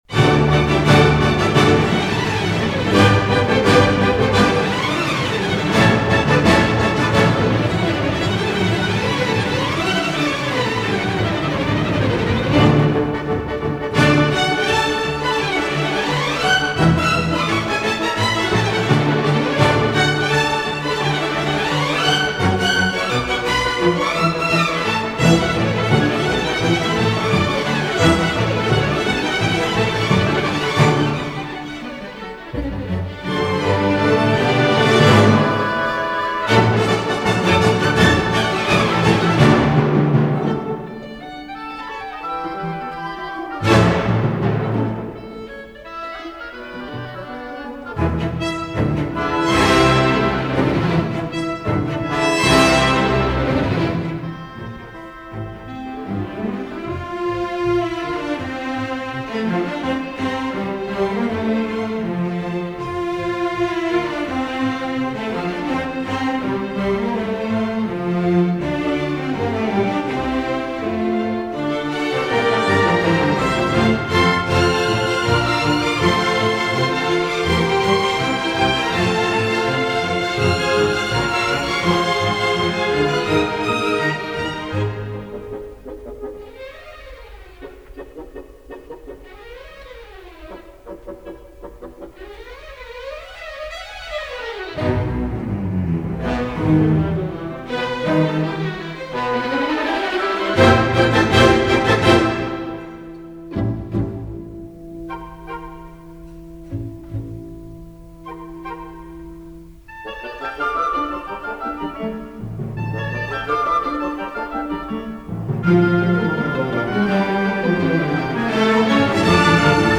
Классическая музыка